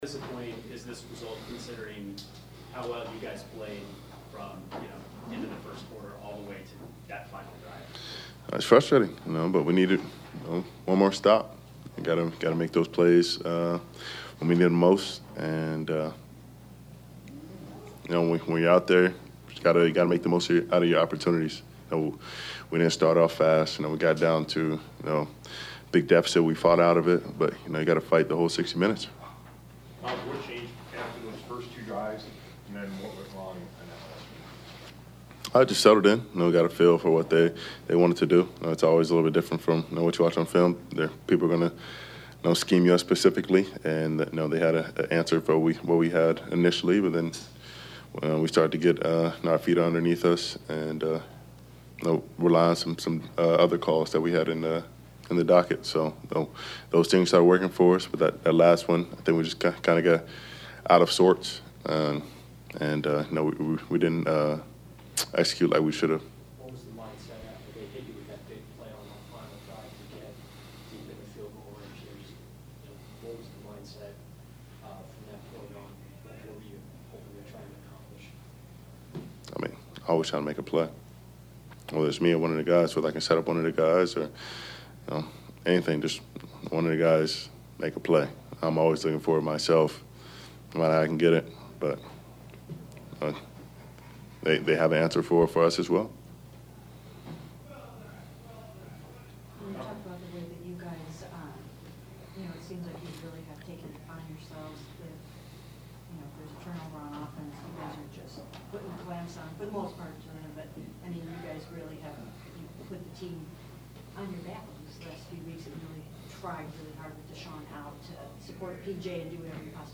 Browns lose game at Seattle in final minutes, 24-20; Myles Garrett speaks to media in Post-game Press Conference